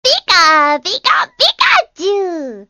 Звуки пикачу